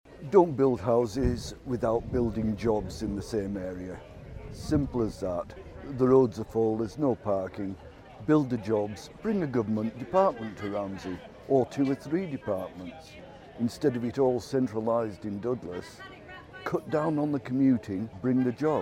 At this weeks Government conference a huge emphasis was put on members of the public coming along to have their voices heard.